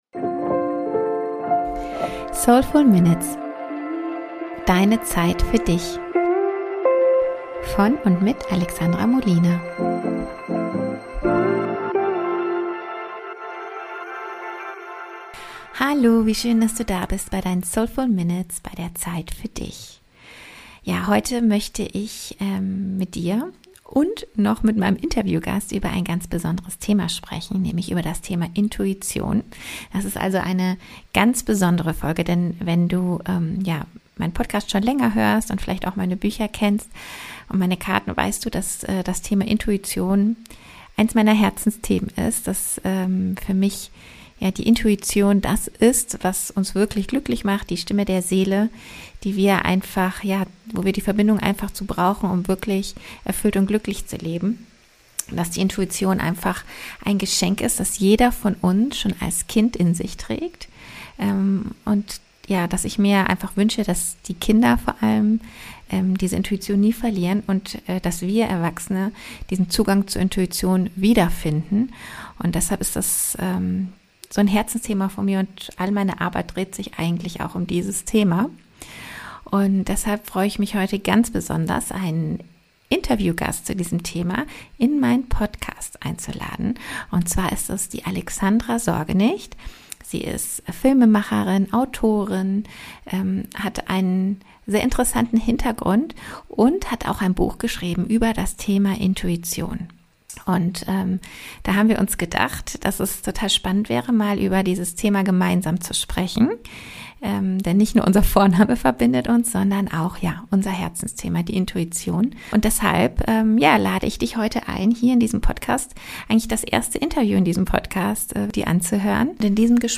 Heute gibt es etwas ganz Besonderes für euch – ein Gespräch